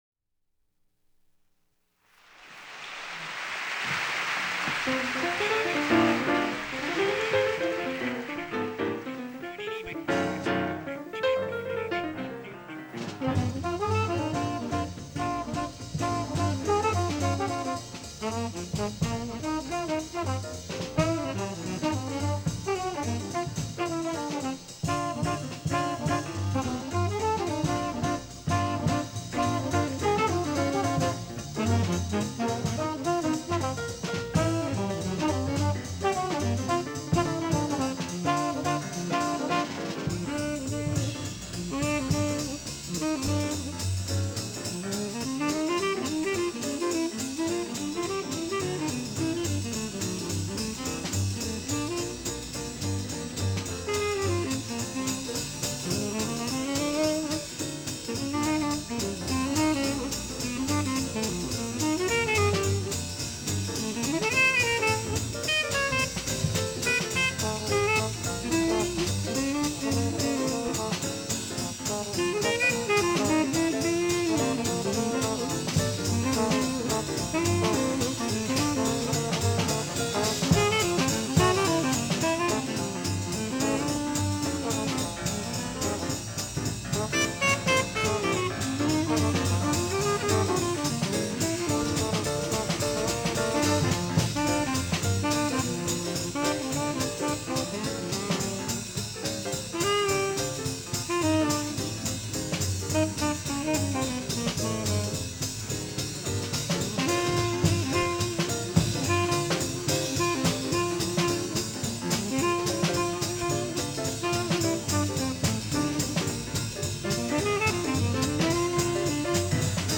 Tenor Saxophone
Trombone
Bass
Drums
Guitar
Piano